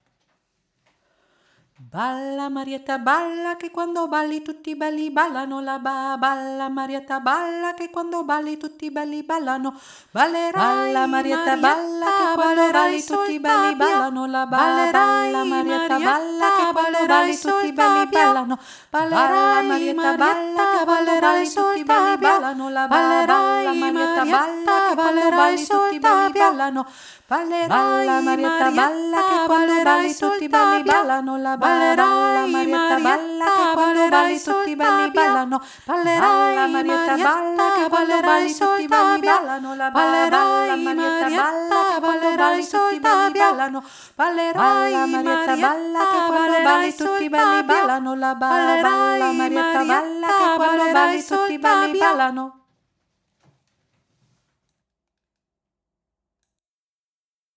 Balla Marietta italienisches Tanzlied
Hauptstimme Kanon
marietta-kanon-hauptstimme.mp3